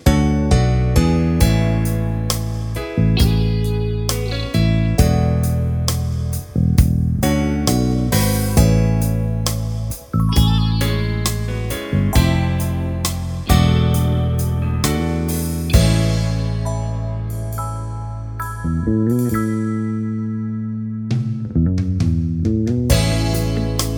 no Backing Vocals R'n'B / Hip Hop 4:25 Buy £1.50